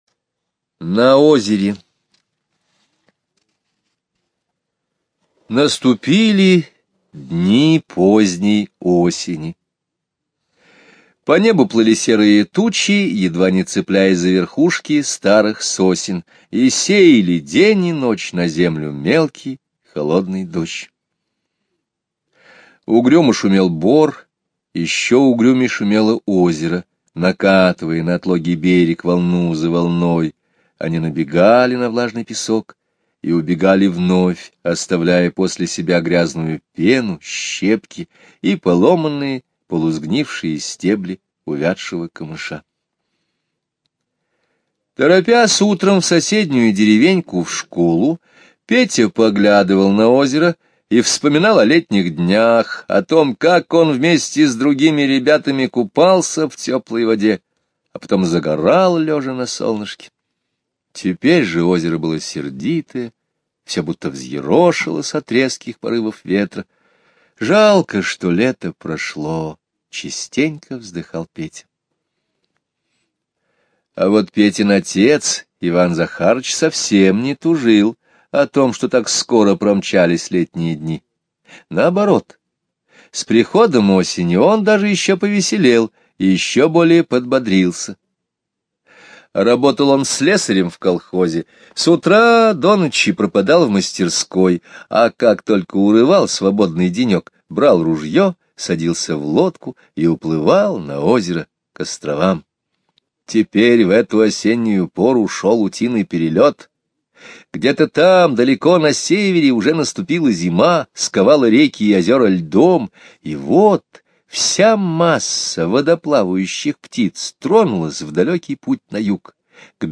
Слушайте аудио рассказ "На озере" Скребицкого Г. онлайн на сайте Мишкины книжки. Рассказ о том, как осенью меняется природа. На озере появились перелетные утки, летевшие из тундры на юг. skip_previous play_arrow pause skip_next ...